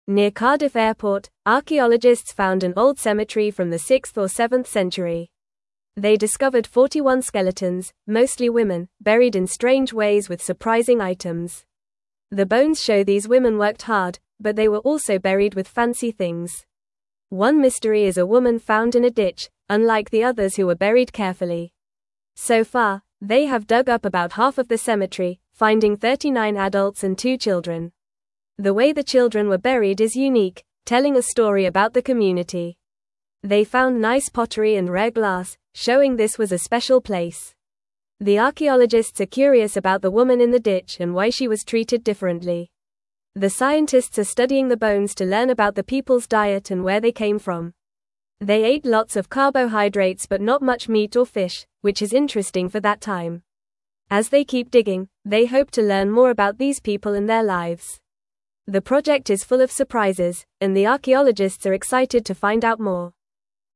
Fast
English-Newsroom-Lower-Intermediate-FAST-Reading-Old-Cemetery-Found-Near-Cardiff-Airport.mp3